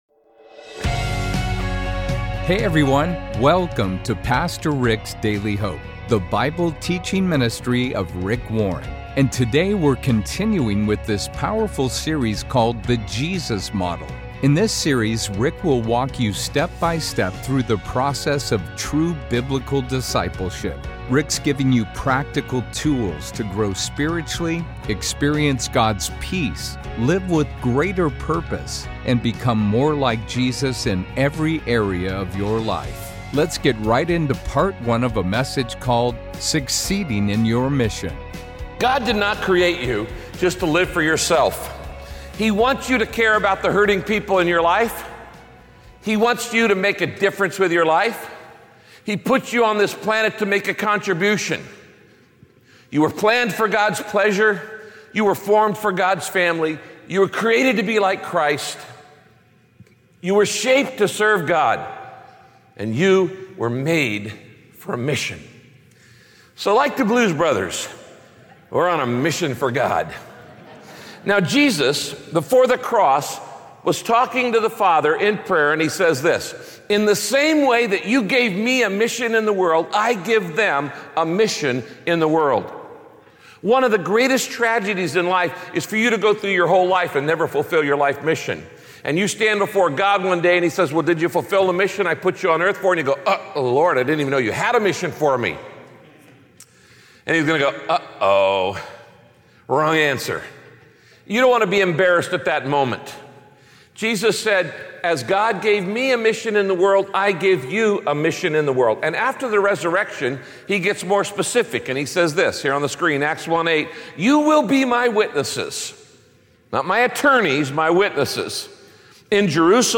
When you're trying to reach your friends and family or even an unreached people group with the gospel, you need to follow Jesus' example. Pastor Rick teaches in…